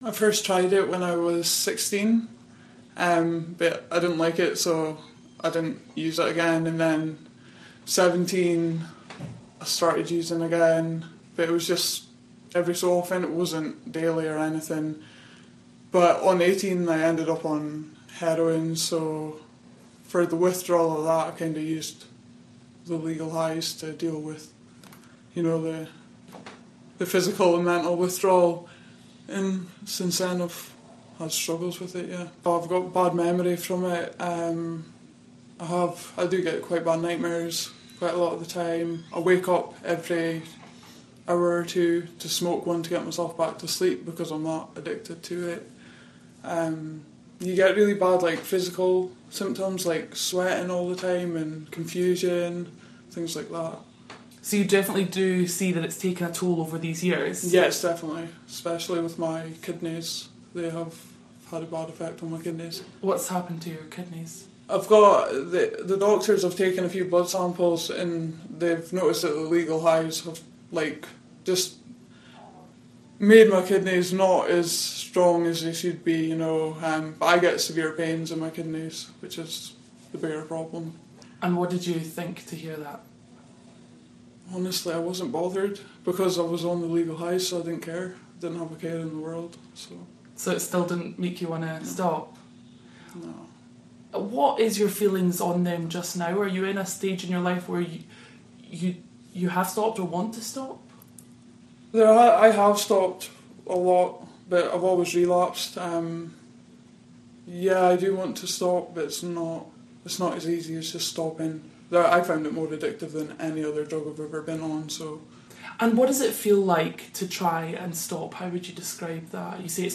NEWS: Woman hooked on legal highs tells her story